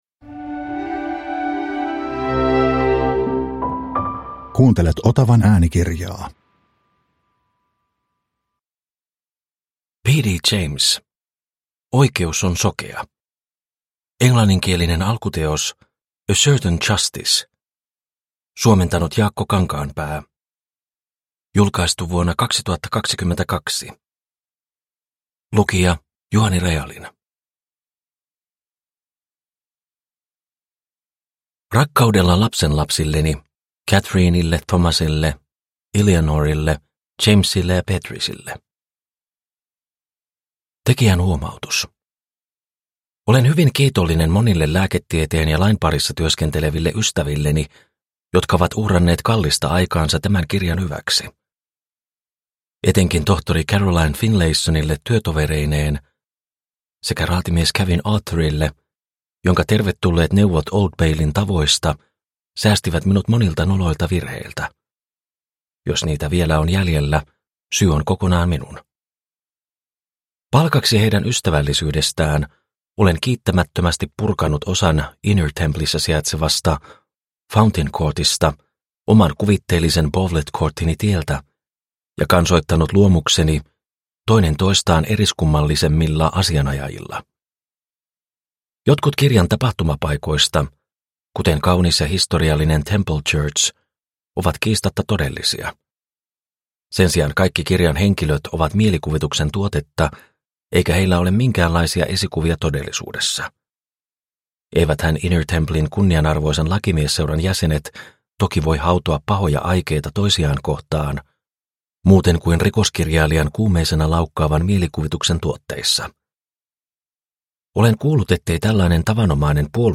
Oikeus on sokea – Ljudbok – Laddas ner